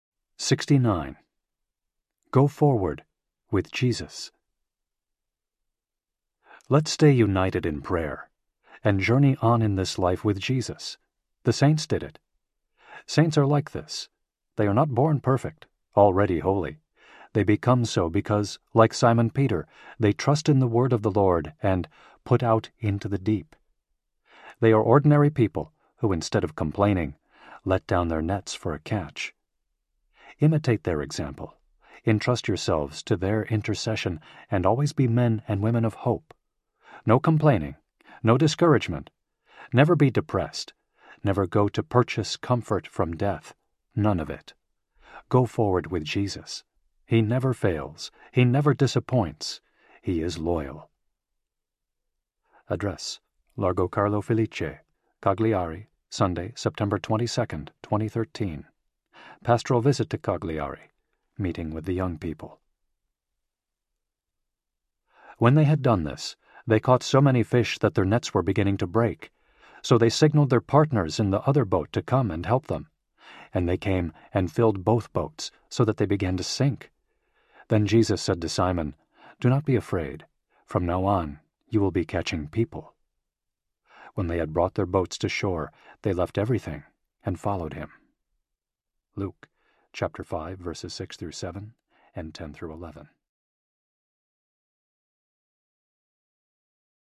Reflections from Pope Francis Audiobook
Narrator